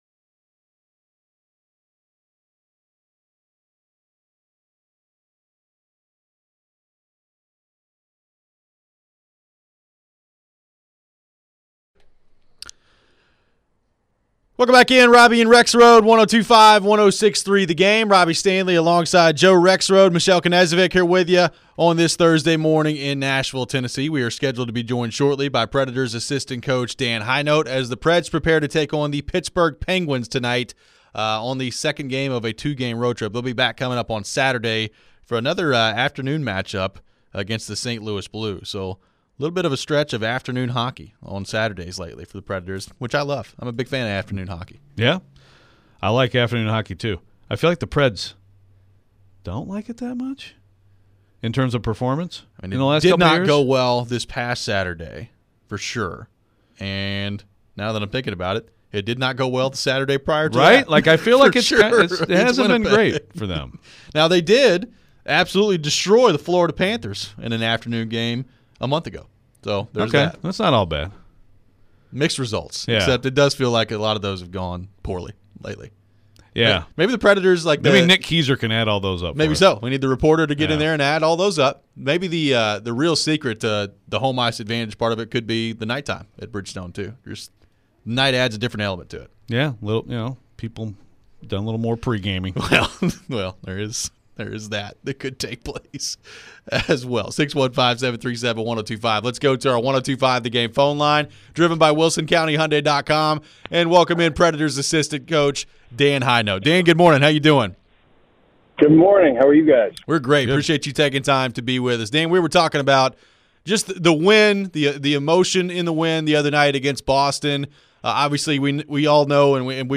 Interview with Preds Asst.